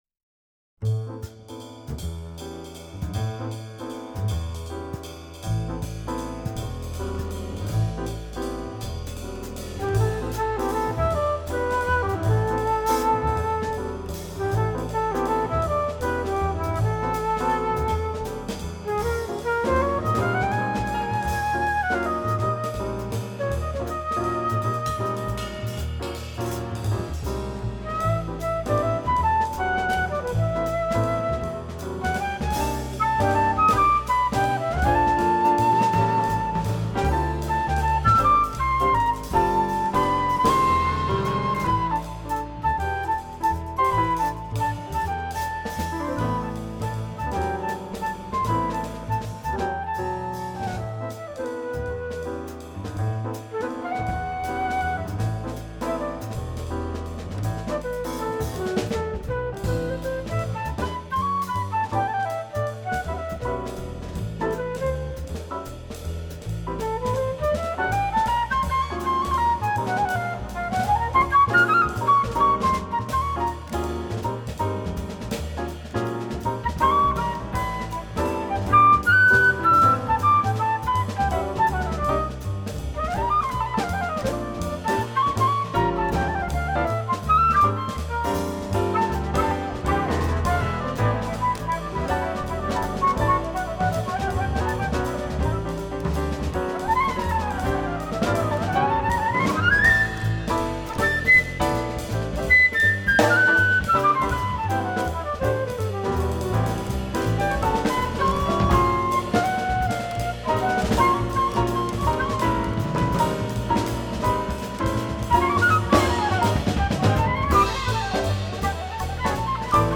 flute
piano
bass
drums